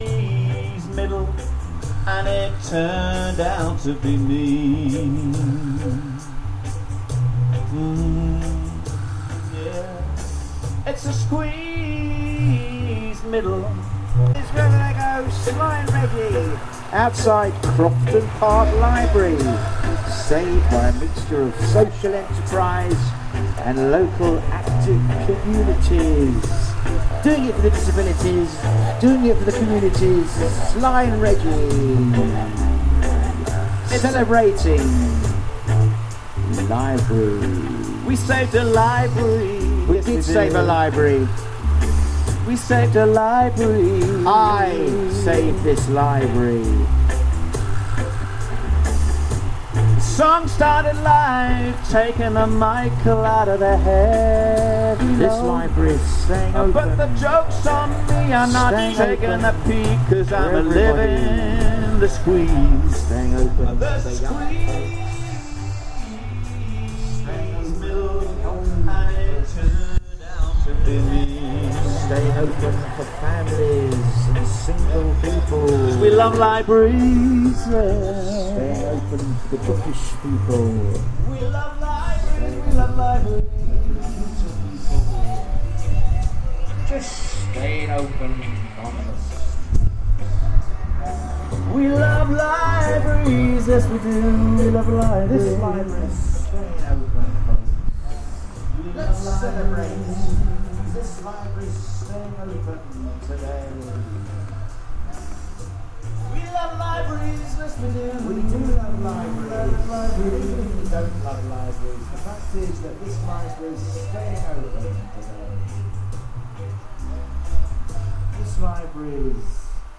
Middle class sounds system turned up to sing a song they prepared for Crofton Park Community Library, great fun